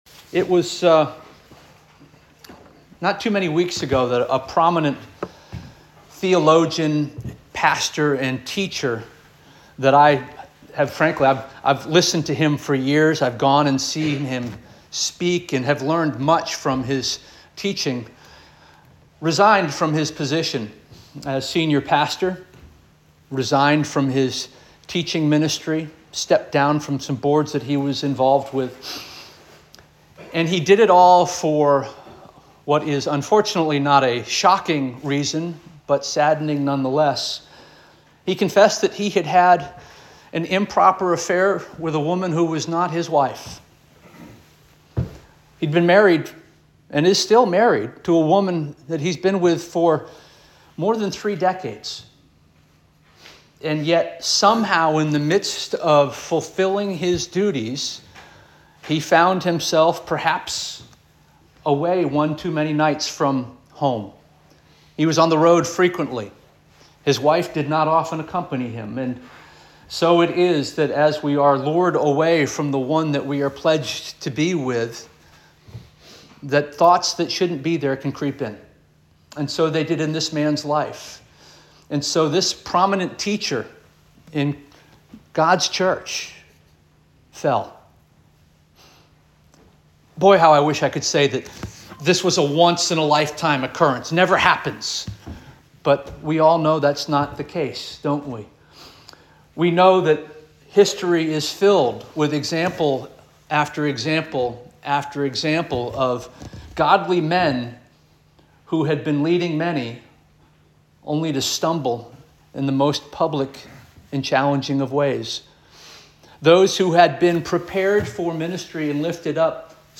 October 20 2024 Sermon - First Union African Baptist Church